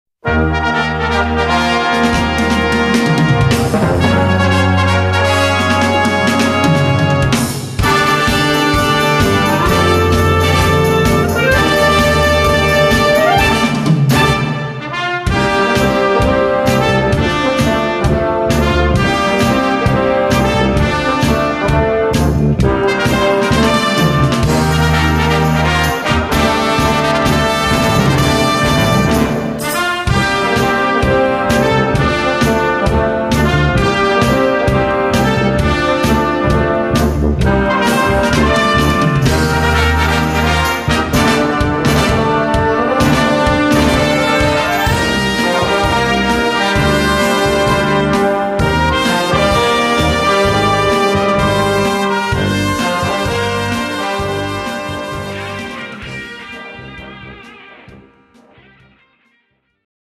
Voicing: Concert Band - Blasorchester - Harmonie